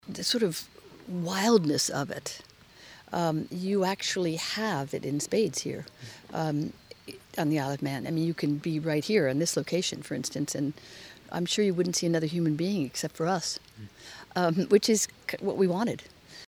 Speaking on set in Tholt-y-Will